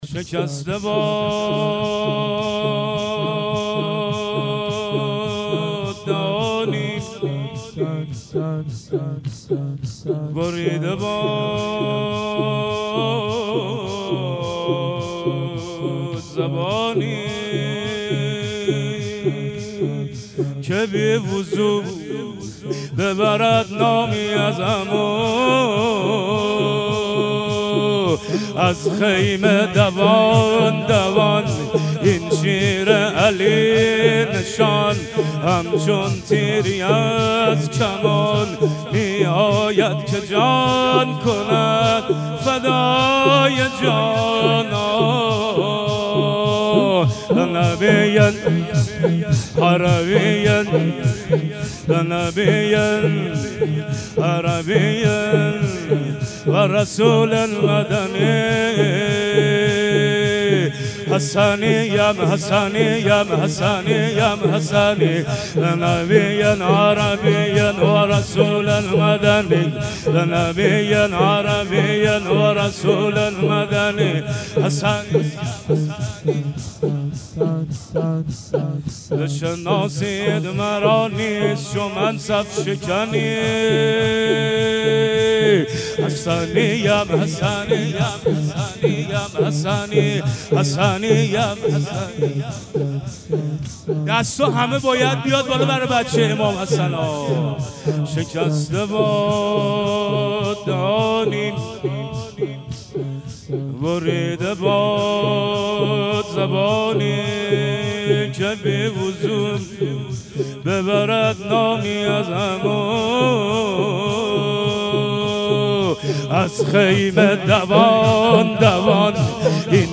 روضه خوانی